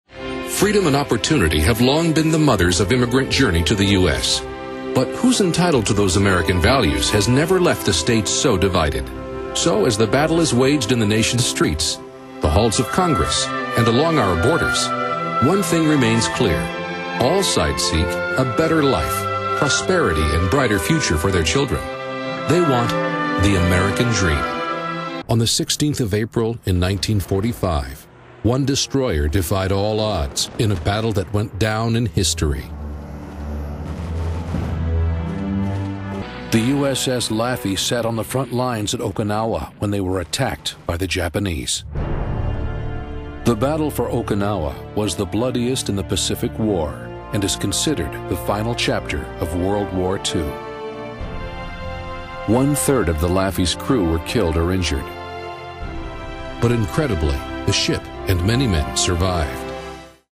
Iconic, Dramatic, Impeccable.
Narration